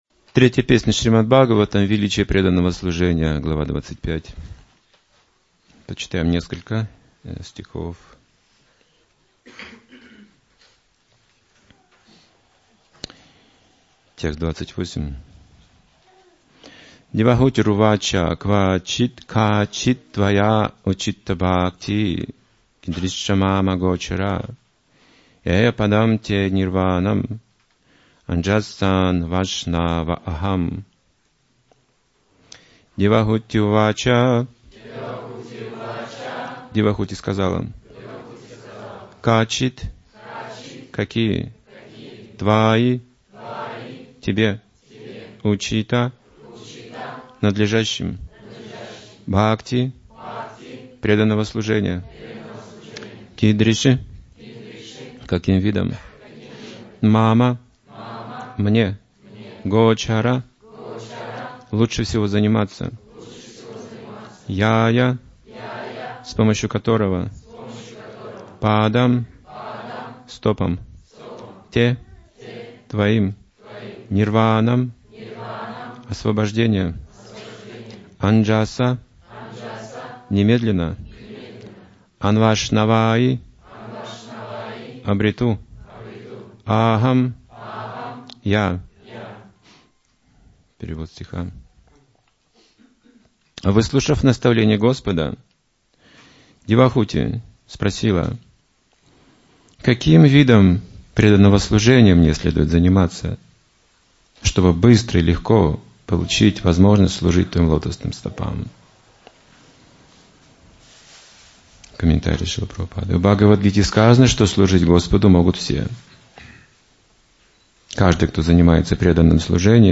Темы, затронутые в лекции: - Освобождение из материального мира - Путь отречения - Как уменьшить наши страдания, путем обретения знания - В чем суть йоги - Естественная склонность к бескорыстному служению любого живого существа - Безмерное счастье бхакти, которое превосходит освобождение - Уникальные возможности преданного служения для каждого - Огромная внутренняя сила и слава преданного - Непобедимая энергия бхакти - Искусство жизни - Великий дар святого имени